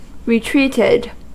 Ääntäminen
Ääntäminen US Haettu sana löytyi näillä lähdekielillä: englanti Käännöksiä ei löytynyt valitulle kohdekielelle. Retreated on sanan retreat partisiipin perfekti.